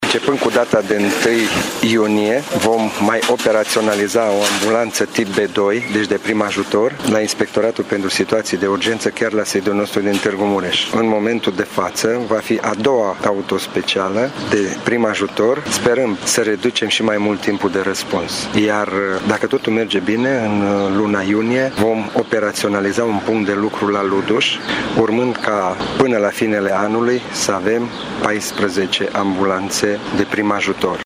Punctul SMURD de la Luduș va fi dotat cu o ambulanță de prim ajutor tip B2 și o autospecială de lucru cu apă și spumă. De asemenea, aici funcționează și un serviciu de voluntariat pe lângă Primărie, a declarat șeful ISU Mureș, Dorin Oltean: